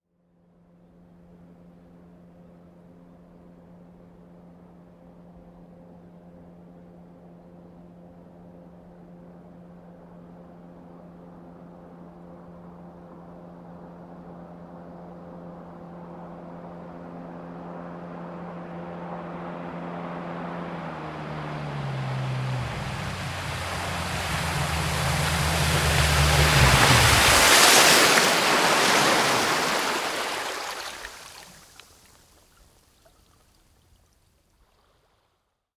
Шум мотора вдали